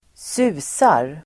Uttal: [²s'u:sar]
susar.mp3